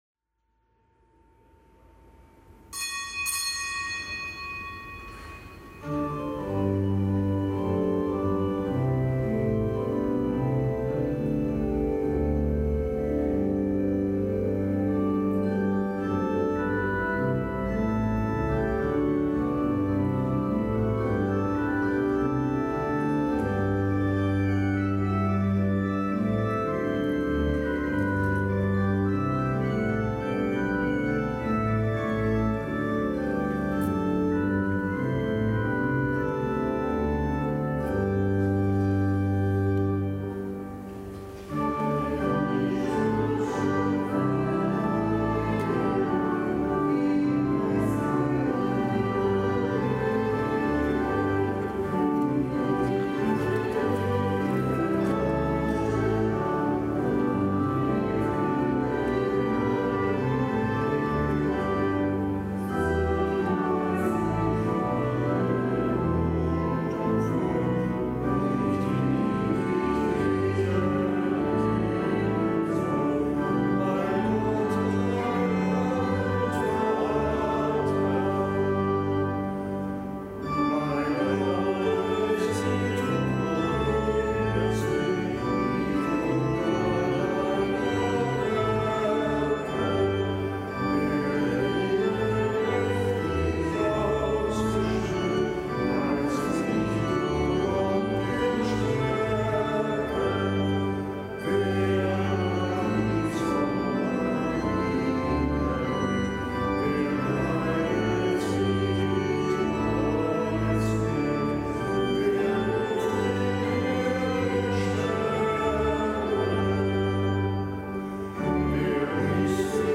Kapitelsmesse am Mittwoch der elften Woche im Jahreskreis
Kapitelsmesse aus dem Kölner Dom am Mittwoch der elften Woche im Jahreskreis.